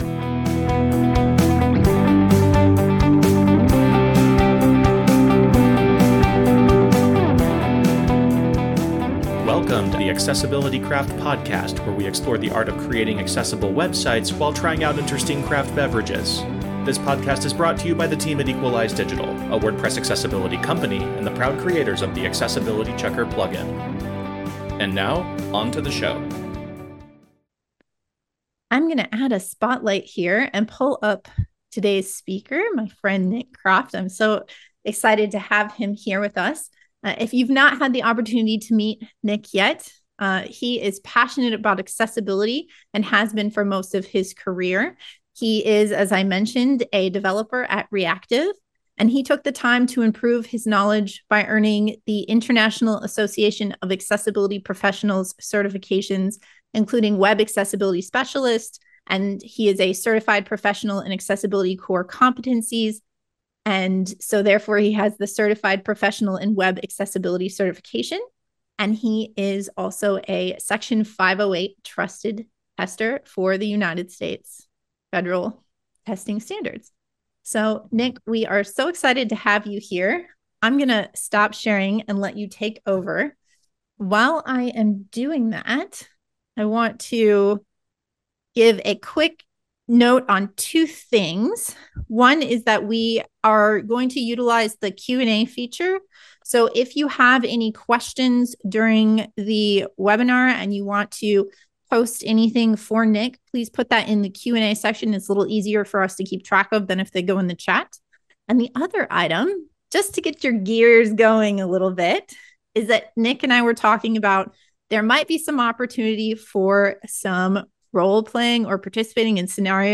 WordPress Accessibility Meetups take place via Zoom webinars twice a month, and anyone can attend.